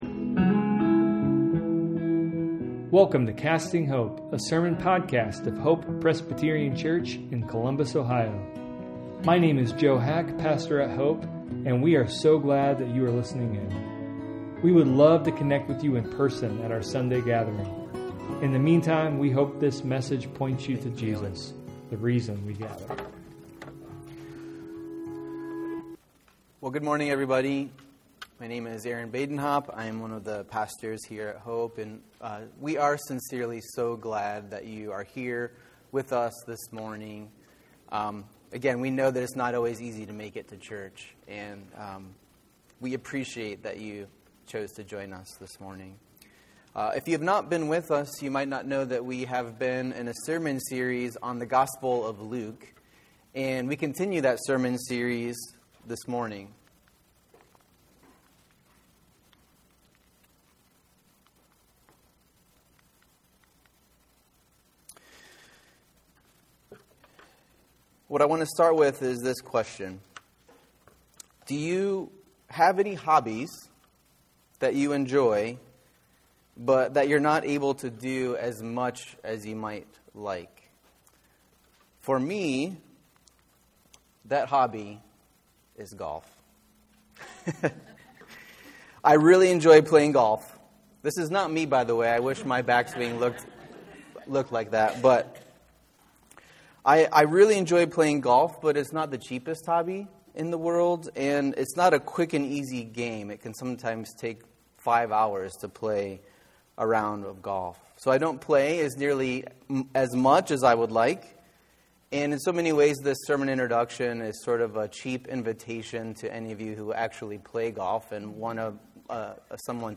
A sermon podcast of Hope Presbyterian Church in Columbus, Ohio.